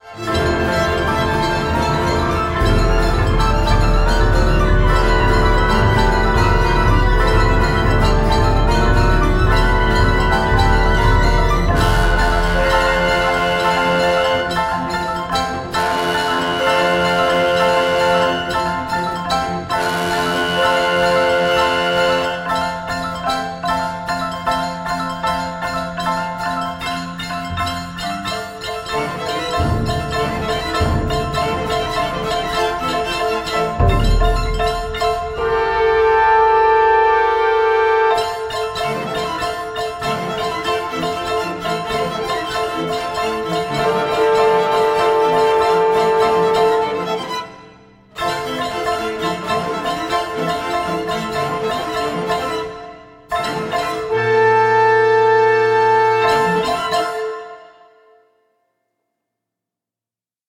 flute
oboe
clarinet
bass clarinet
bassoon, contrabassoon
horn
trumpet
trombone
marimba
toy piano and percussion
quarter tone harp and percussion
piano and harpsichord
violin
viola
cello
contrabass